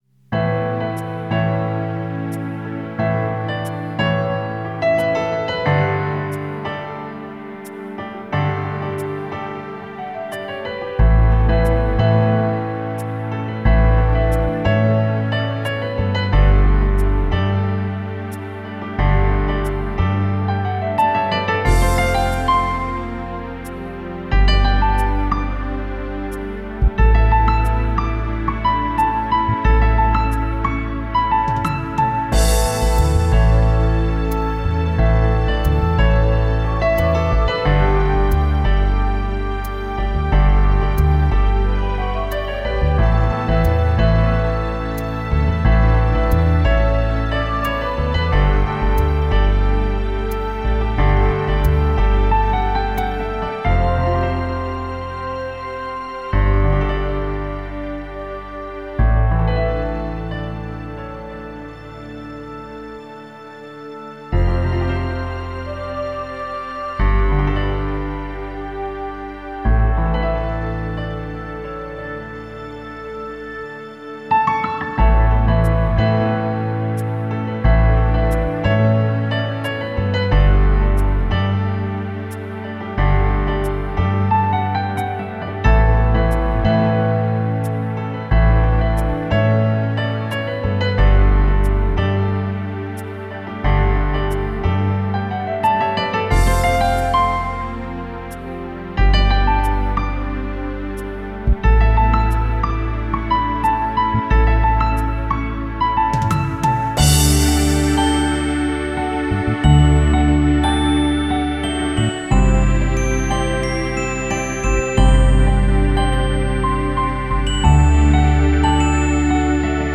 4/4 time, 89 bpm
A very airy piano track, very appropriate for a ballad.